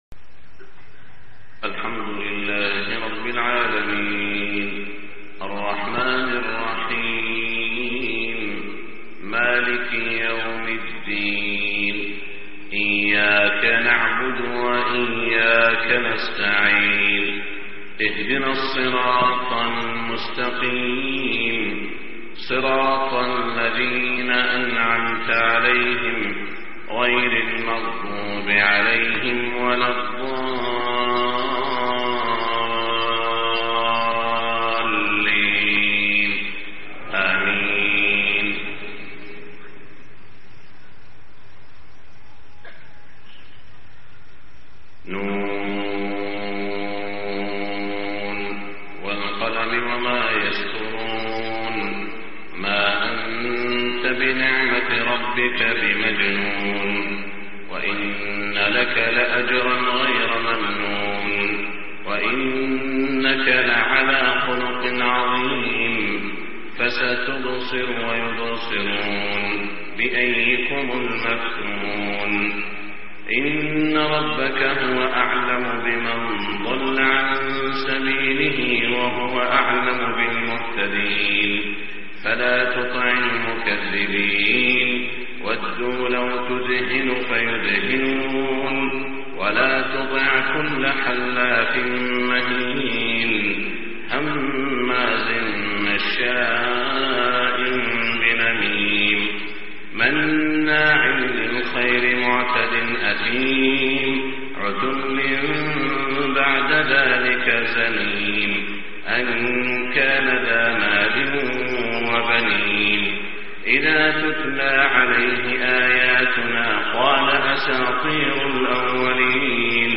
صلاة الفجر 7-5-1426 سورة القلم > 1426 🕋 > الفروض - تلاوات الحرمين